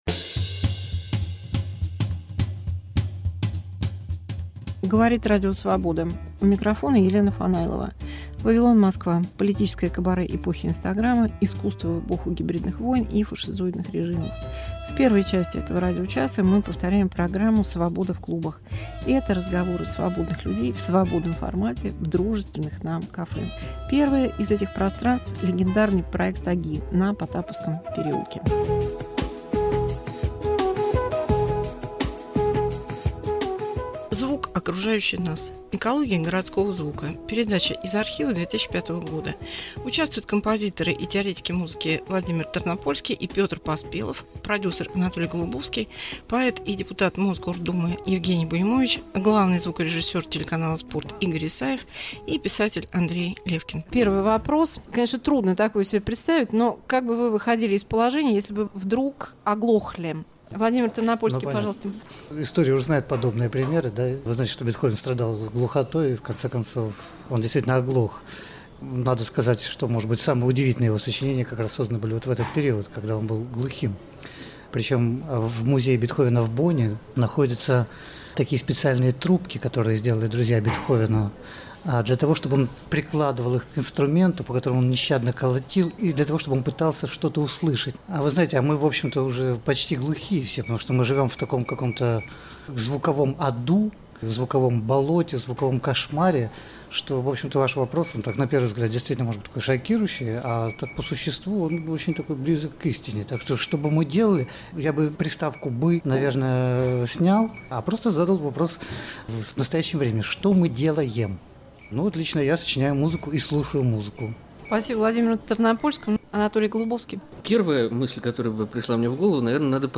Об экологии звука в большом городе - композиторы, звукорежиссеры, писатель и поэт-депутат.